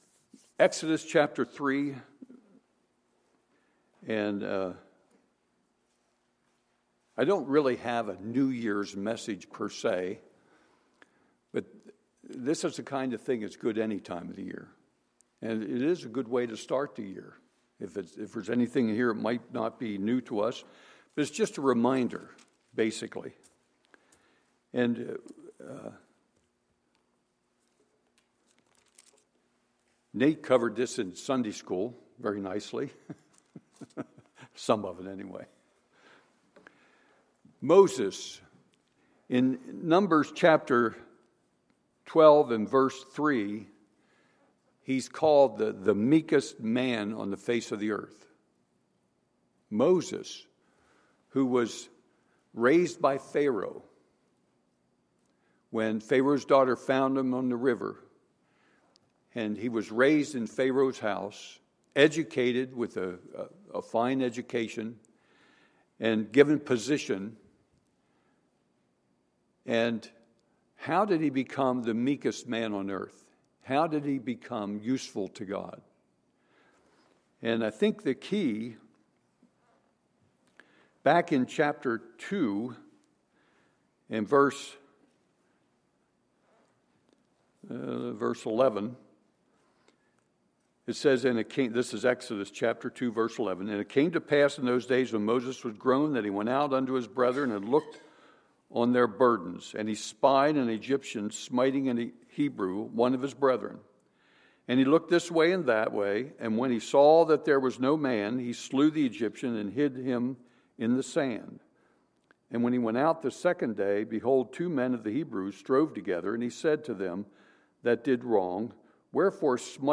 Sunday, January 3, 2016 – Sunday Morning Service